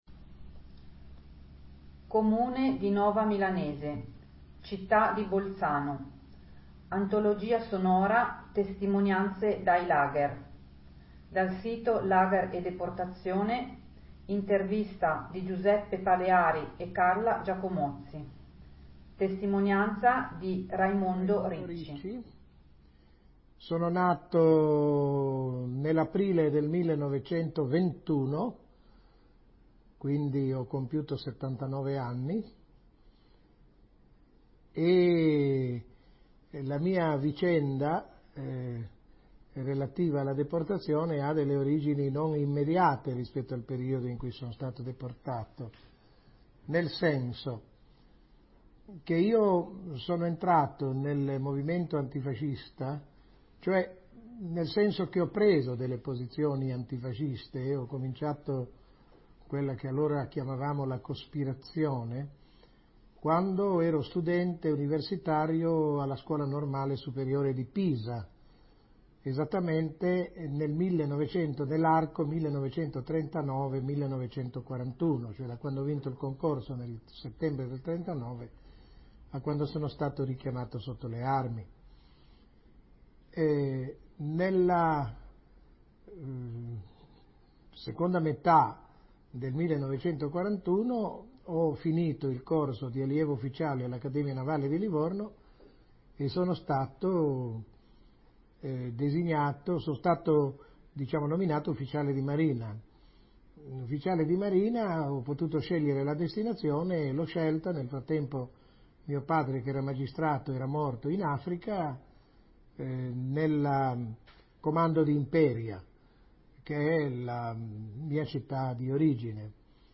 Intervista del: 12/06/2000 a Genova